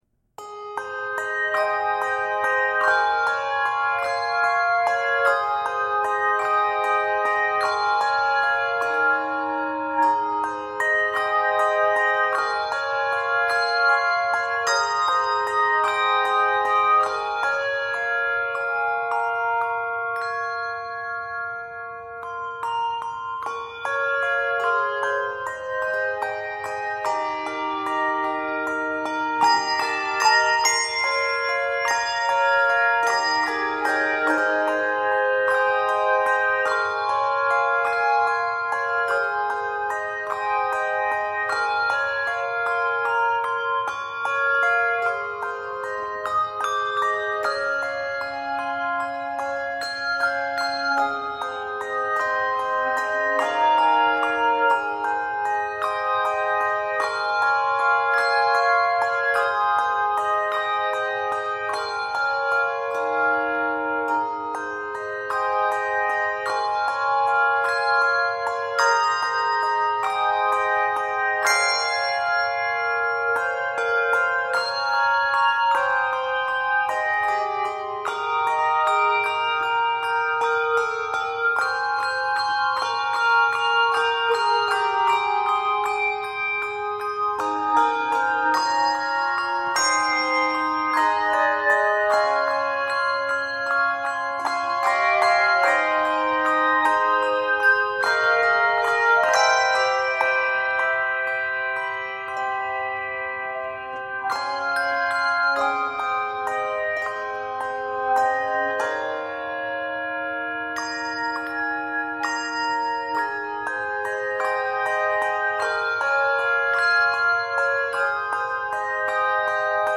Key of Ab Major.